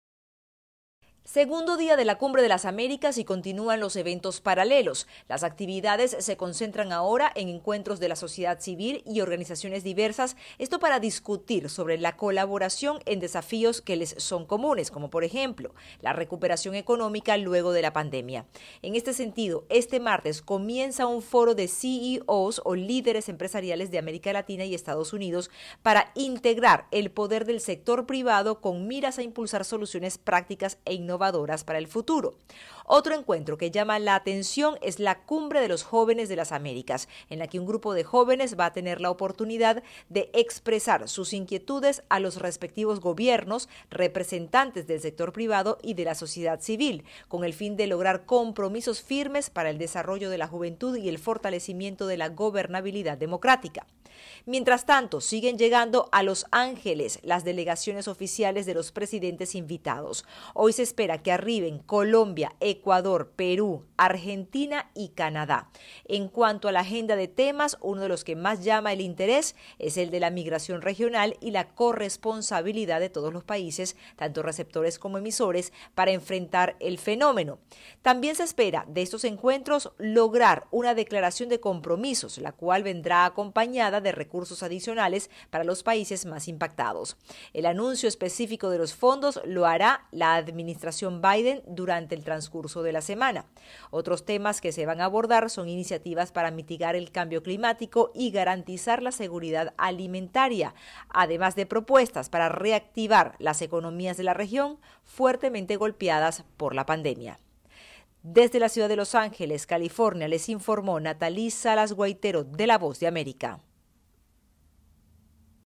El gobierno de México envía una delegación presidida por el canciller Marcelo Ebrard mientras la oposición critica al presidente López Obrador por su inasistencia al foro internacional. Desde México informa la corresponsal de la Voz de América